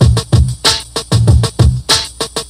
Drum Loop (Doomsday).wav